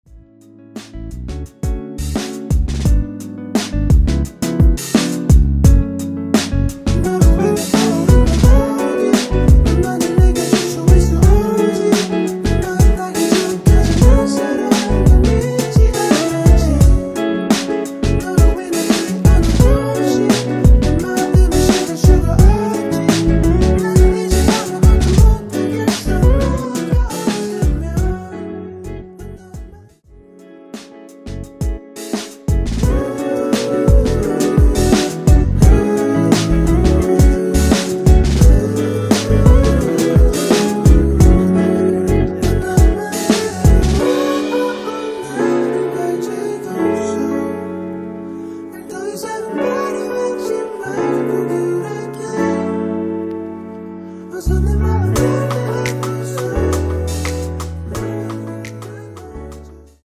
원키에서(-1)내린 코러스 포함된 MR입니다.(미리듣기 참조)
F#m
앞부분30초, 뒷부분30초씩 편집해서 올려 드리고 있습니다.
중간에 음이 끈어지고 다시 나오는 이유는